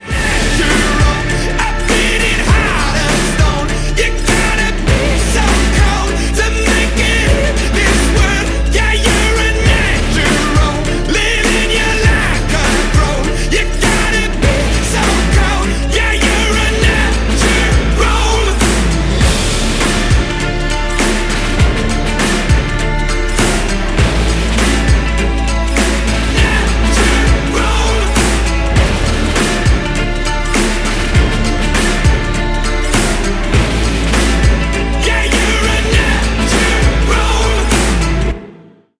• Качество: 128, Stereo
ритмичные
громкие
красивая мелодия
энергичные
alternative
indie rock